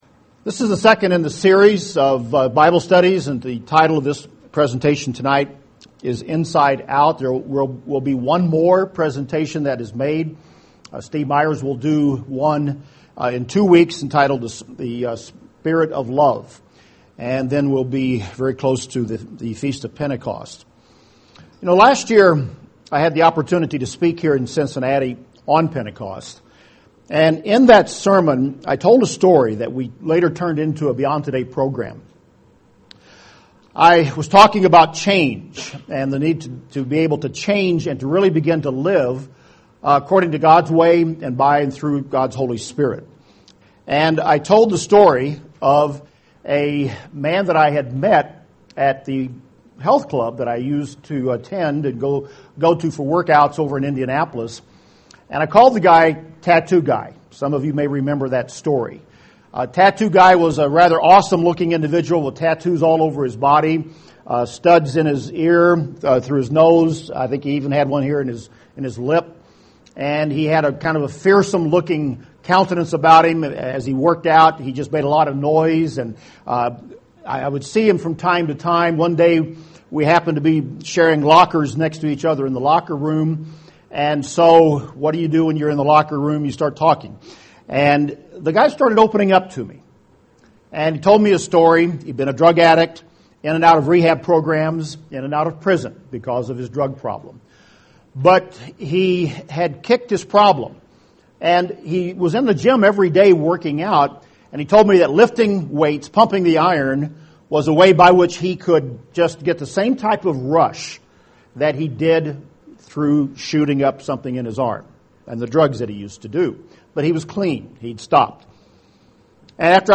This is the second part in the Bible study series: Power to Change.